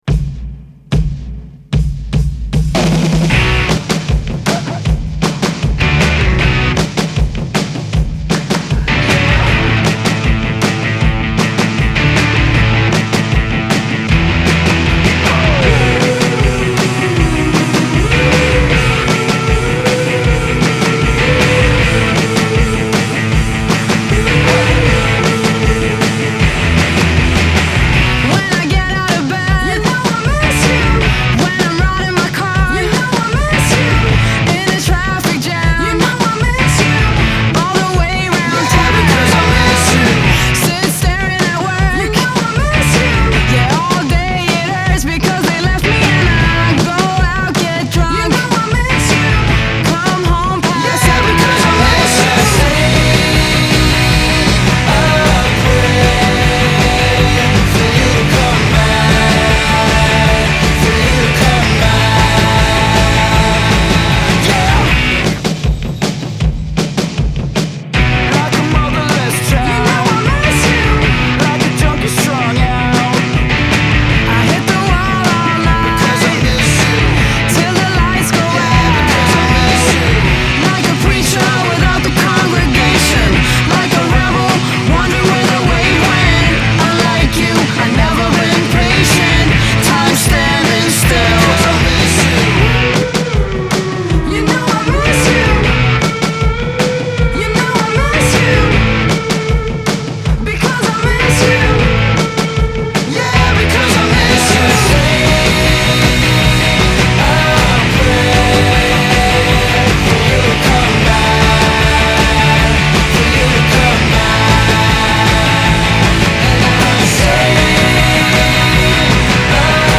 Genre: Indie Pop, Rock, Female Vocal